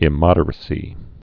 (ĭ-mŏdər-ə-sē)